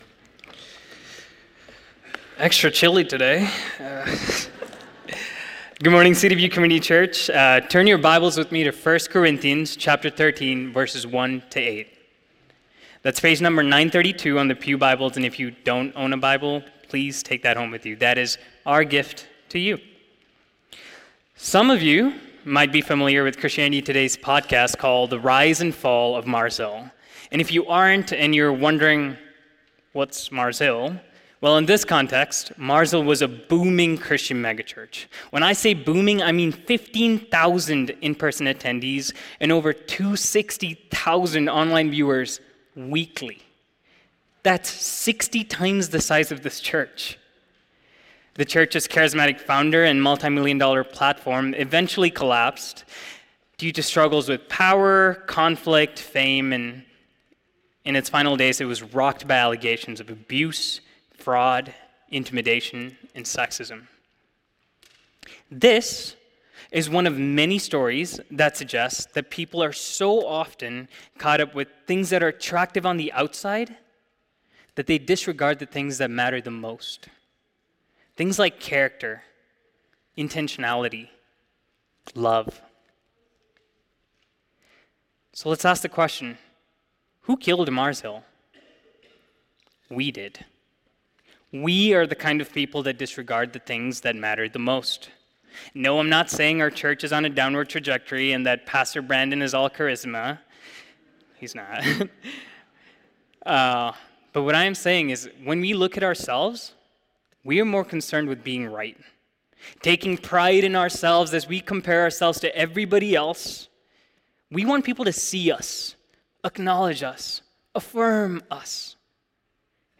The sermon emphasizes the importance of selfless, Christ-like love over self-centered pursuits and affirmation. It contrasts the Corinthians’ misuse of spiritual gifts with Jesus’ example of humble servanthood.